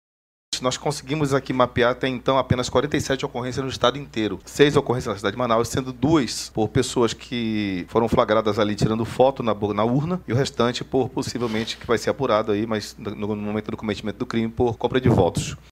De acordo com os números divulgados, durante a coletiva de imprensa, nas primeiras cinco horas do pleito, 20 urnas apresentaram problemas, sendo que 11 precisaram ser substituídas.